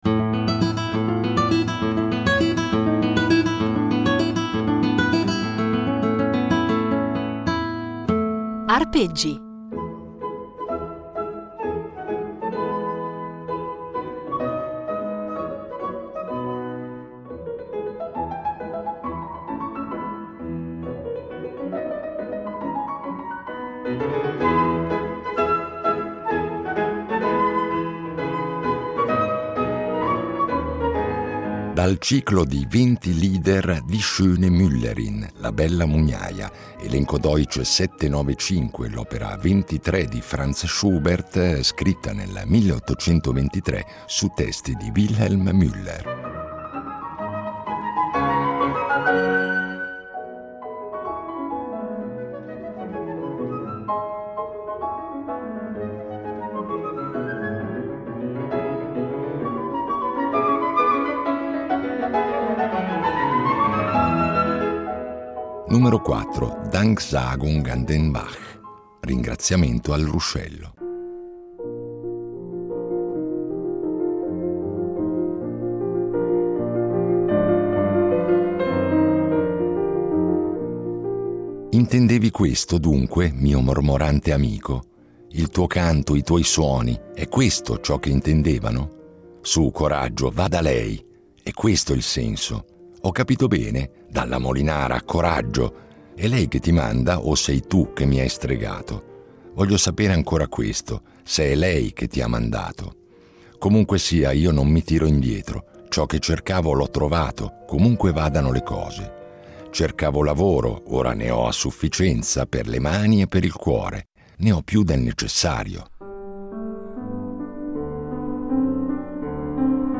Ognuno dei 24 lieder viene quindi preceduto dalla lettura del testo, e quindi eseguito da diversi cantanti e pianisti.